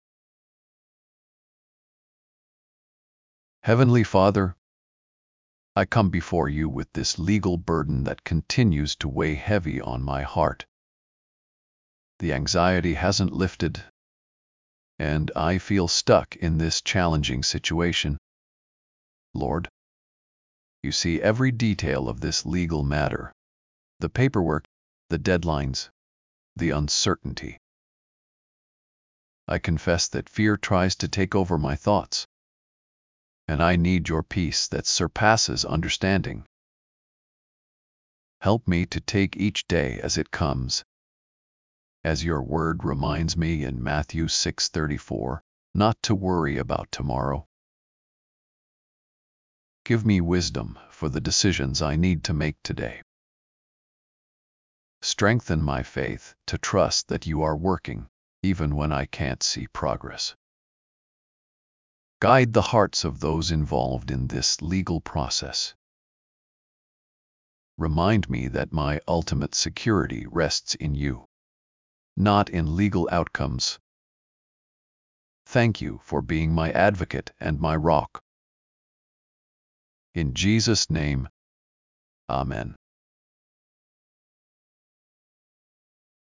1161 -1 Prayer Prayer mood:anxious